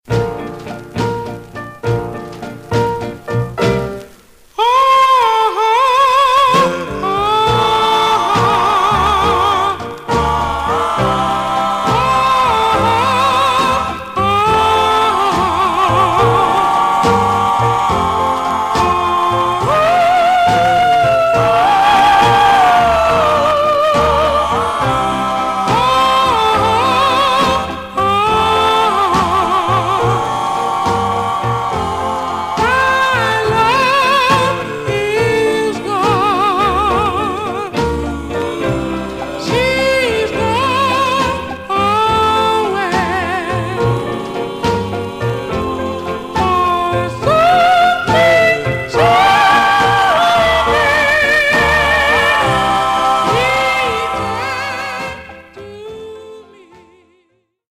Mono
Some surface noise/wear
Male Black Groups